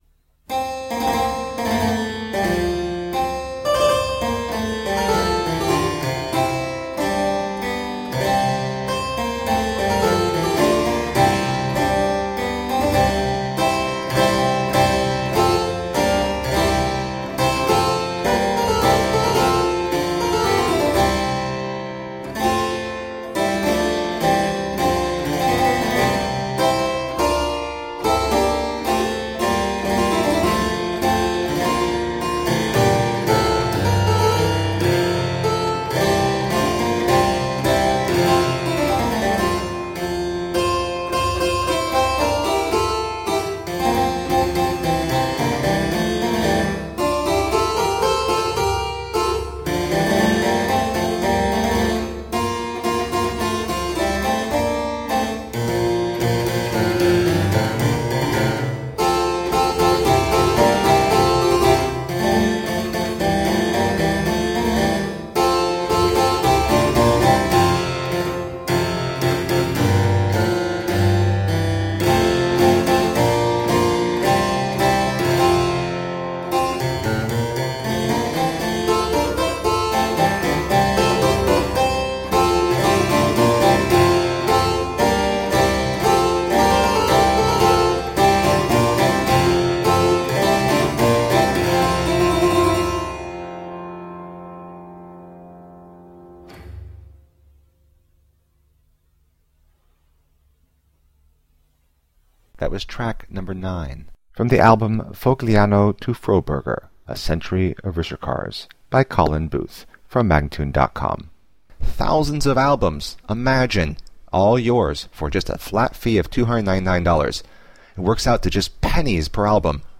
Solo harpsichord music
Classical, Baroque, Instrumental Classical
Harpsichord